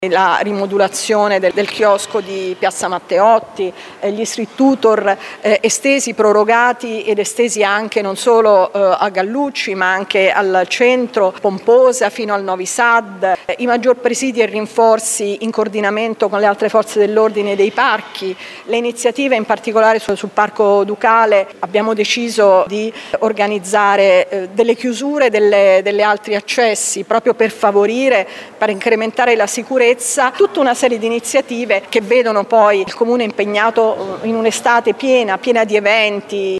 Qui sotto le sue parole